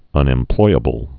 (ŭnĕm-ploiə-bəl, -ĭm-)